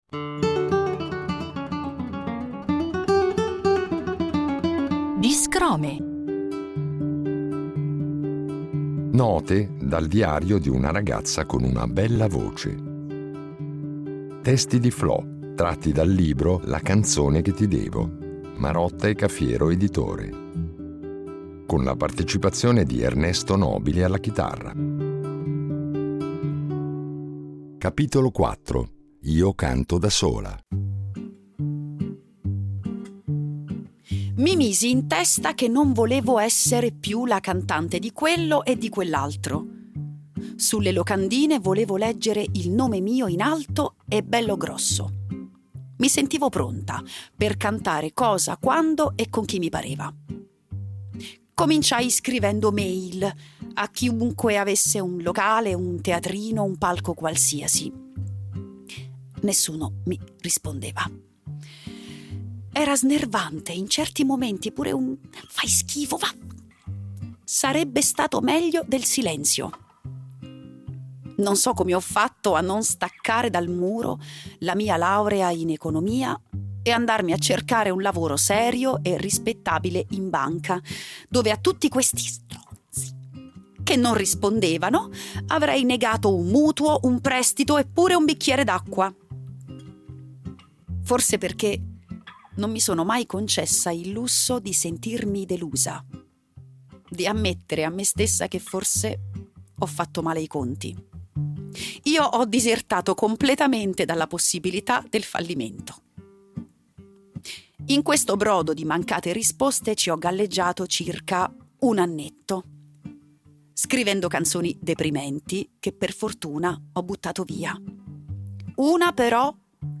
con la voce recitante e cantante